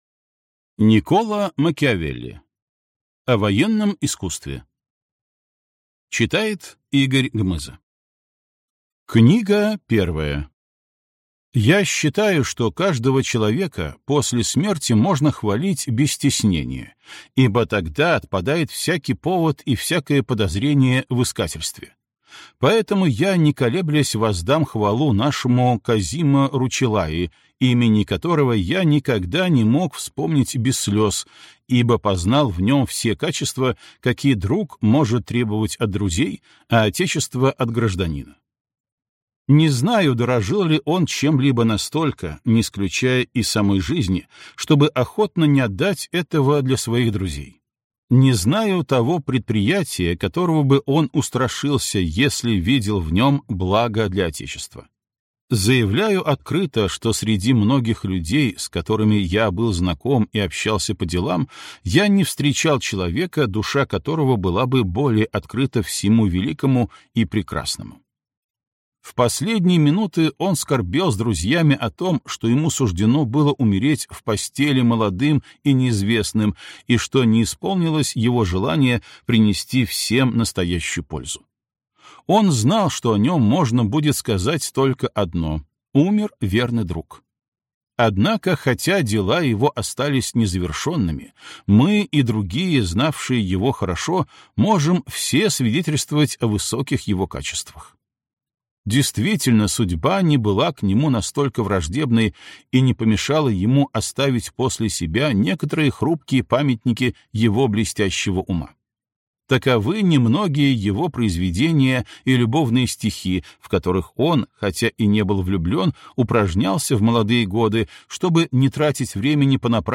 Аудиокнига О военном искусстве | Библиотека аудиокниг
Aудиокнига О военном искусстве Автор Никколо Макиавелли